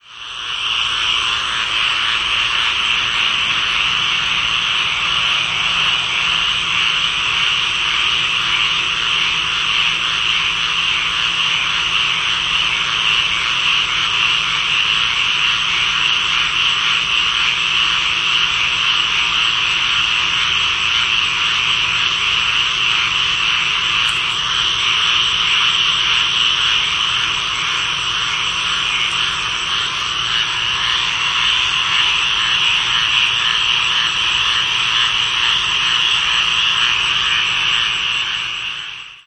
Squirrel Treefrog (Hyla squirella)
The breeding call is a hoarse "quack" that sounds similar to a mallard duck. Males call while floating, sitting on the bank or clinging to emergent vegetation.
This call sounds like the chatter of a squirrel, which is where the common name originated from.